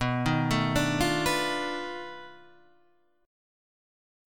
B 7th Sharp 9th Flat 5th